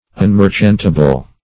Unmerchantable \Un*mer"chant*a*ble\, a. (Com.)